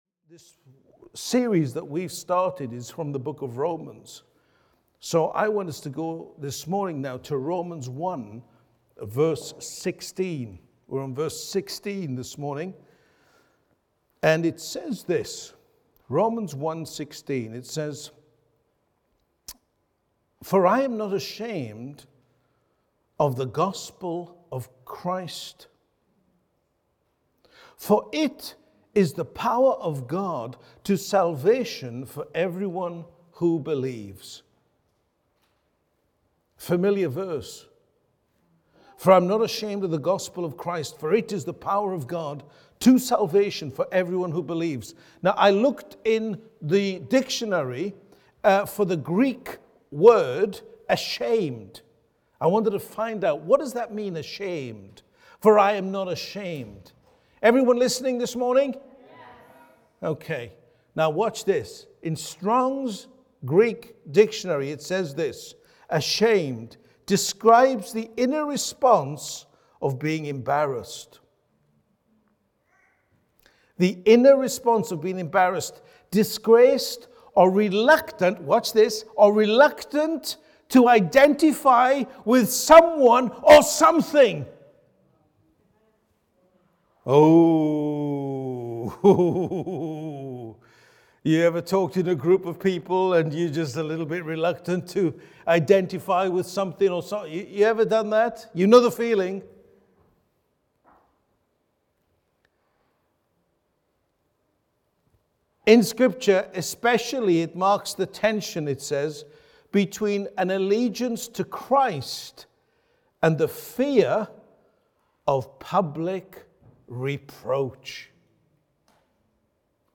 Audio and video teachings from Living Faith Church